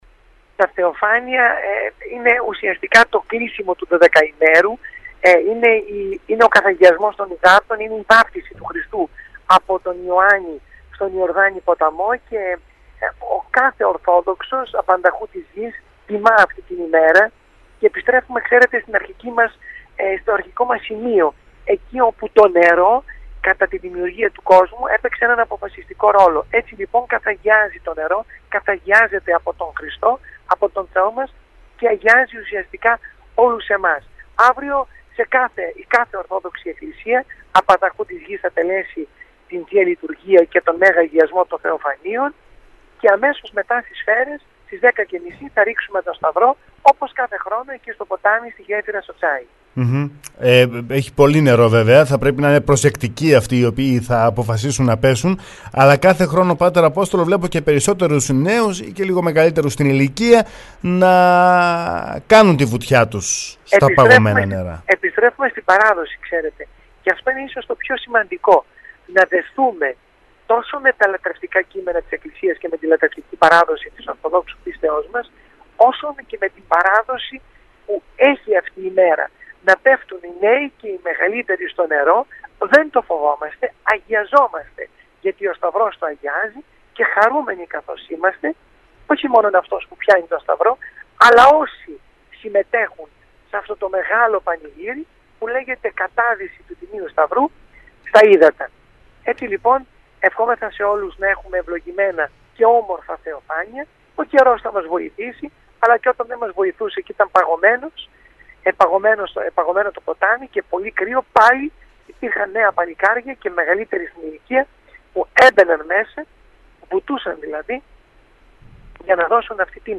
το ραδιόφωνο Sferikos 99,3